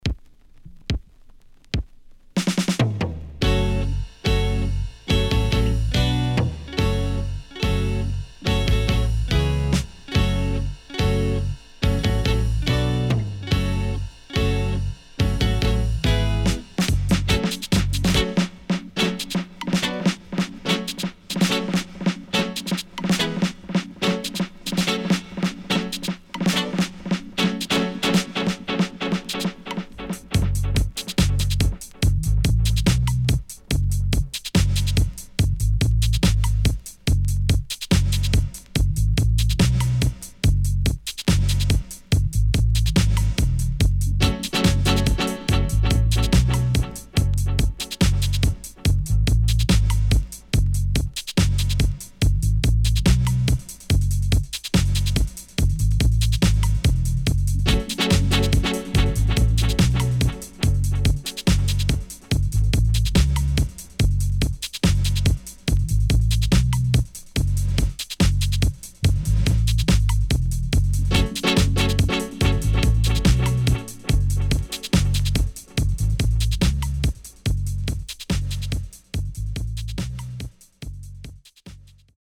【12inch】
Nice Lovers Vocal & Killer Stepper Dubwise
SIDE A:少しチリノイズ入りますが良好です。